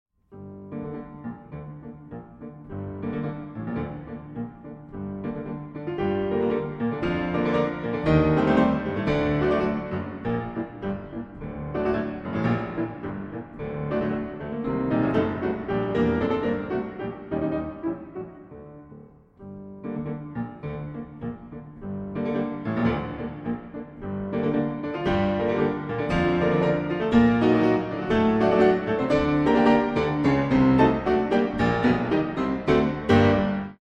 Piano
4 no.5 in G minor 3:46
South Melbourne Town Hall